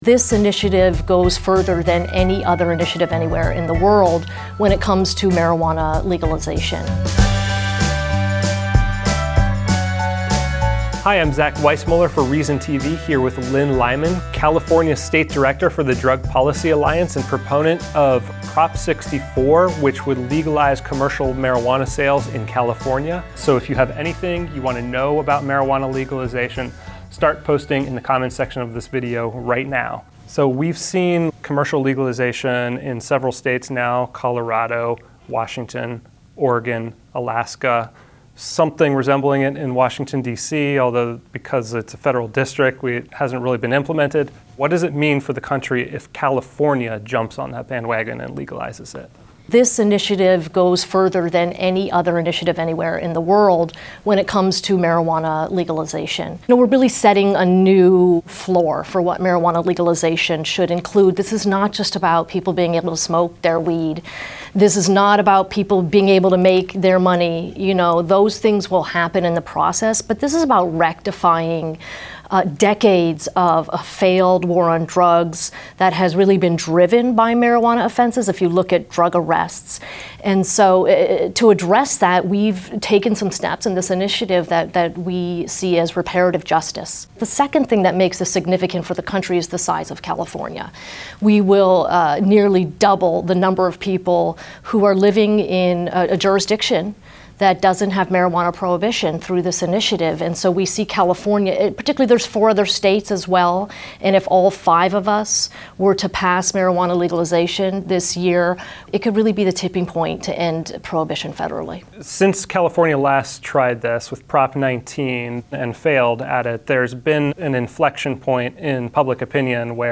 This video originally aired live on Reason's Facebook page on August 10, 2016.